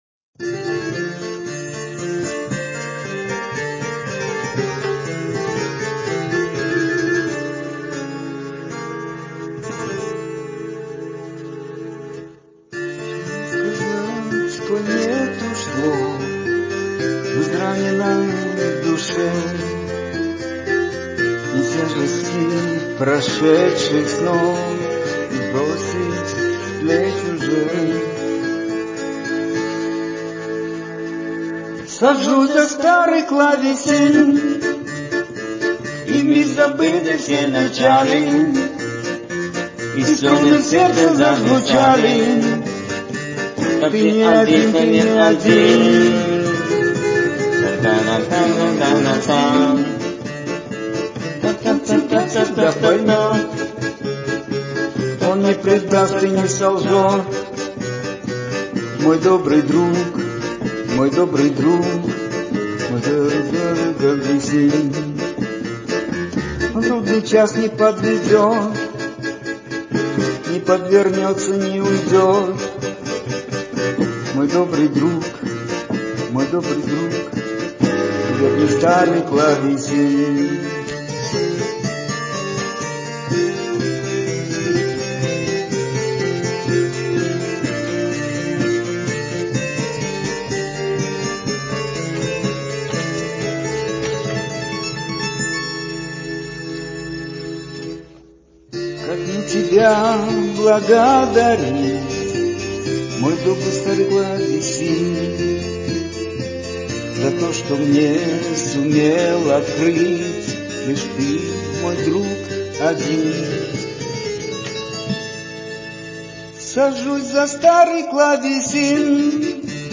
работа над аранж.
На смартфон был записан момент этой работы.